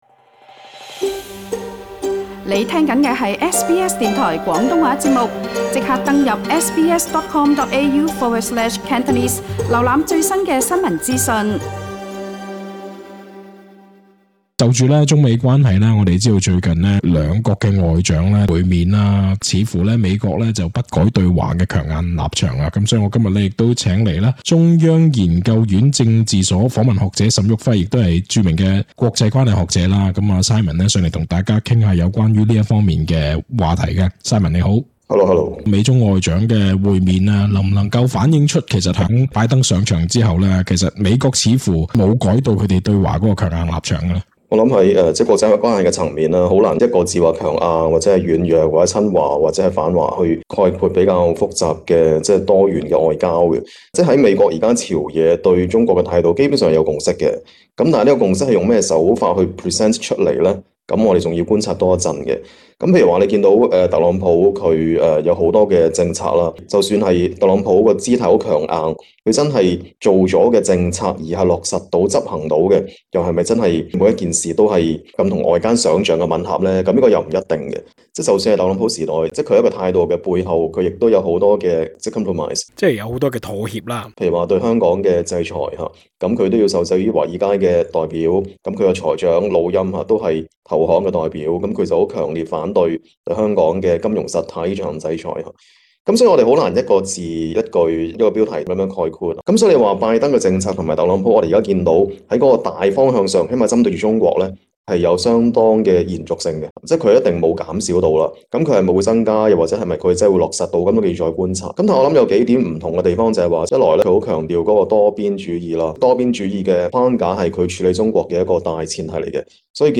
台灣中央研究院政治所訪問學者、著名國際關係專家沈旭暉博士在接受SBS廣東話節目組訪問時就認為，今日今時，我們不能再視澳洲對華立場強硬是單純為配合美國，因為，澳洲自身亦有其戰略考量。